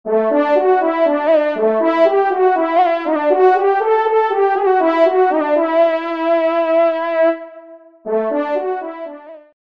FANFARE
Localisation : Sarthe